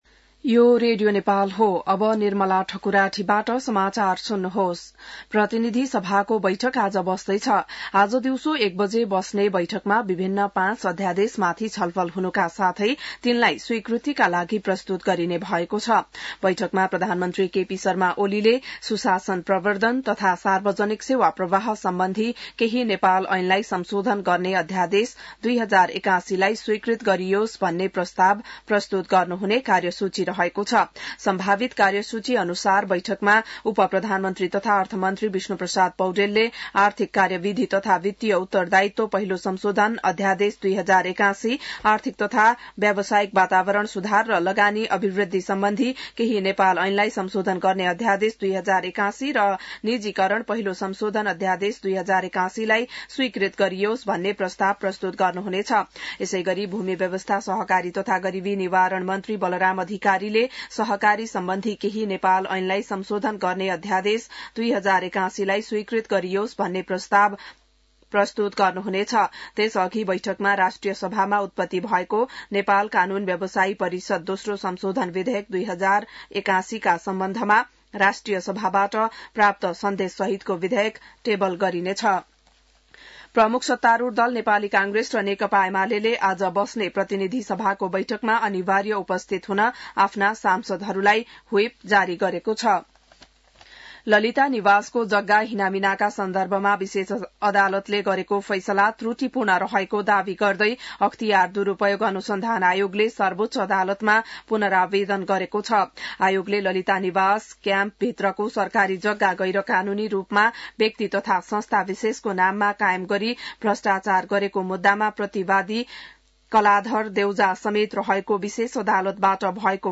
बिहान १० बजेको नेपाली समाचार : २२ फागुन , २०८१